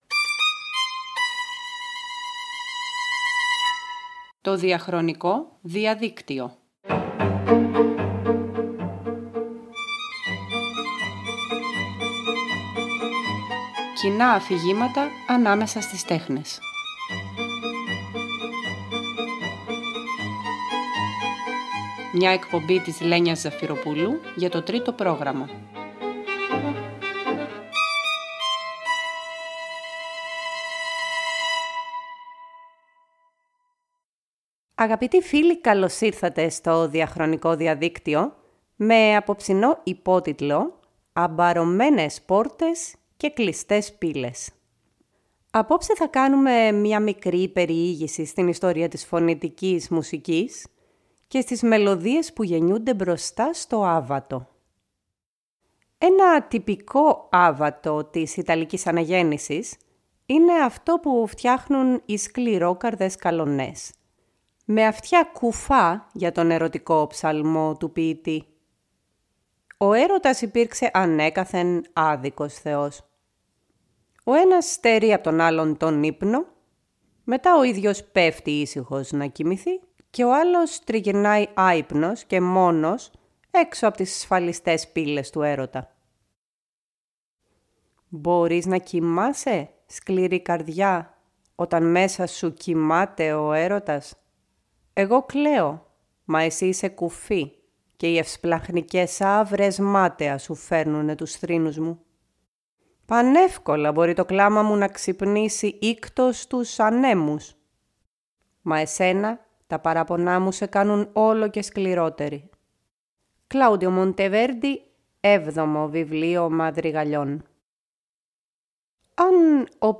Εκπομπή που αναζητά συνδετικά νήματα ανάμεσα στις εποχές και τα έργα.